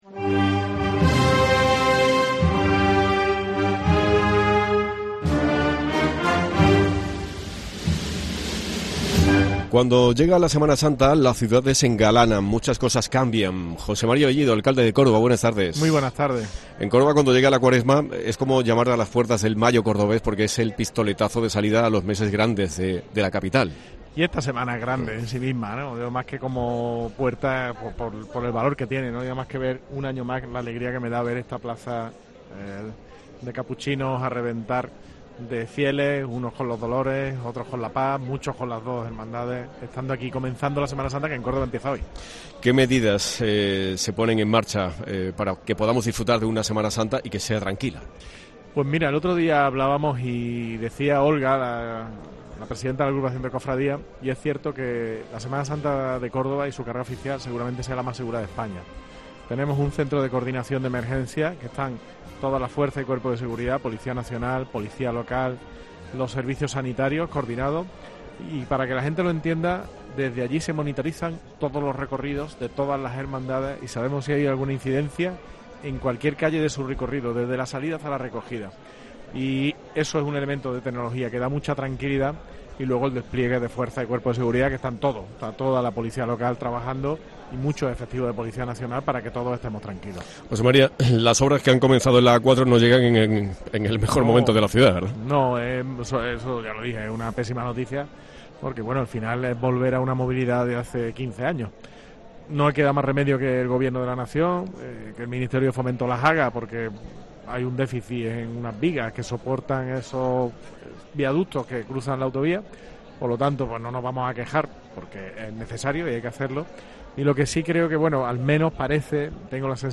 Escucha al alcalde de Córdoba, José María Bellido en el Viernes de Dolores